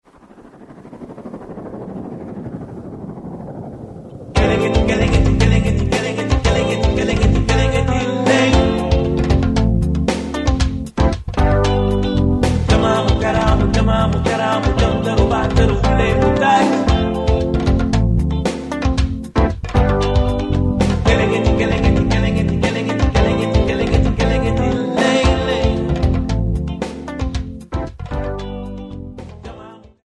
saxophone
sabars, jimbe
chorus
Popular music--Africa, West
Cassette tape